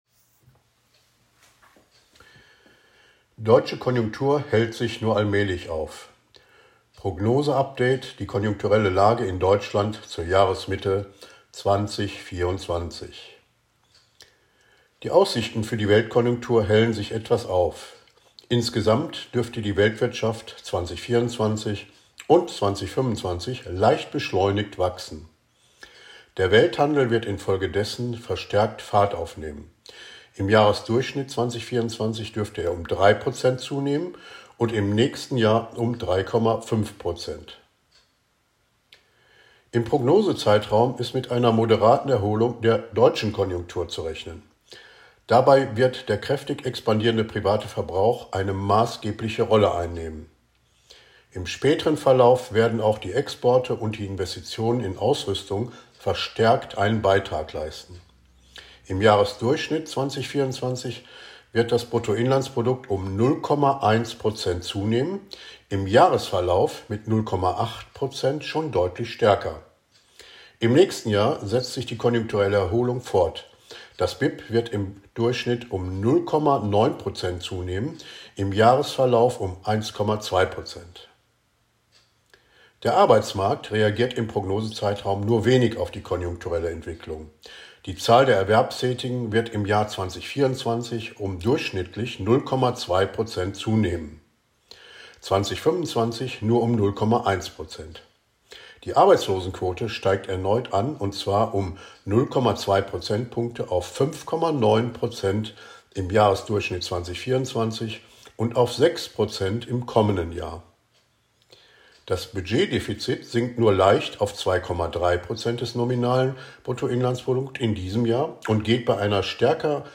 Quellen und Audiostatement